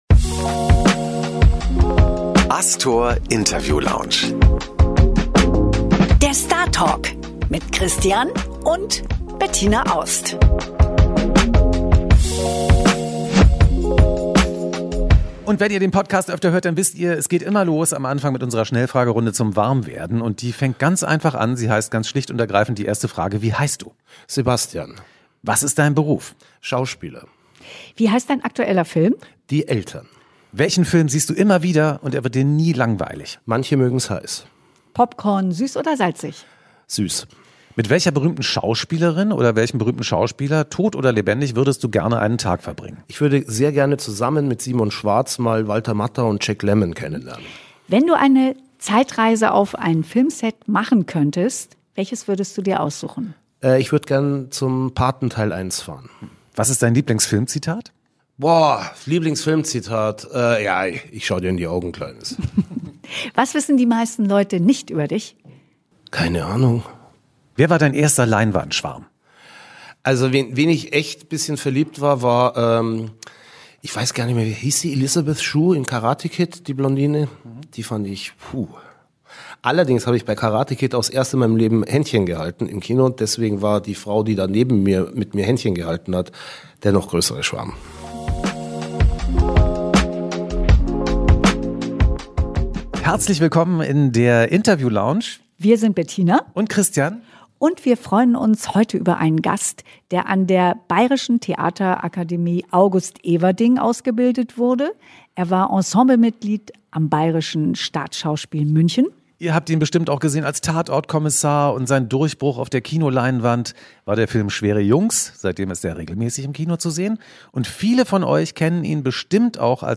Im Podcast Astor Interview Lounge begrüßen ist diesmal den Schauspieler Sebastian Bezzel!